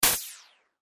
Processed Air Release